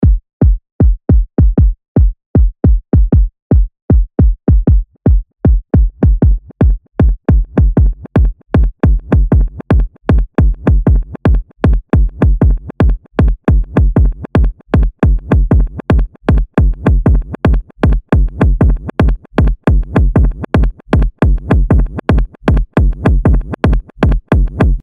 キック単体でもどうぞ。